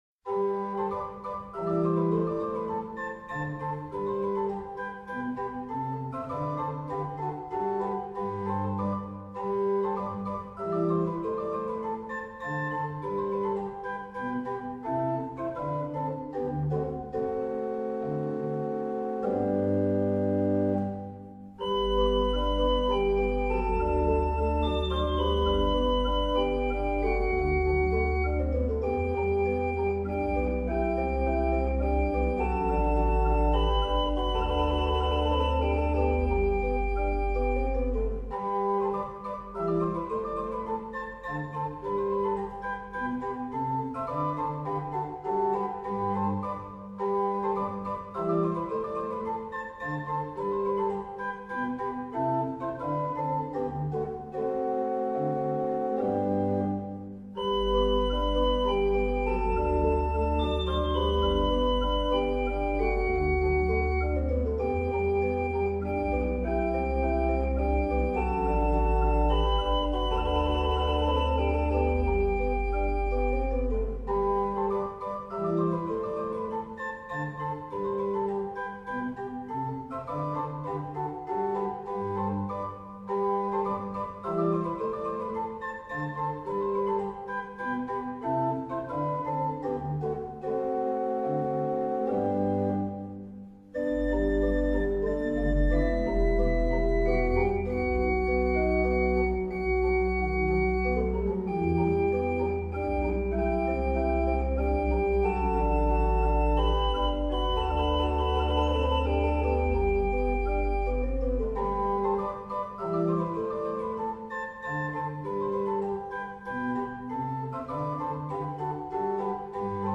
Titel 7: J. C. Kellner – Choralvorspiel: Liebster Jesu, tadalafil wir sind hier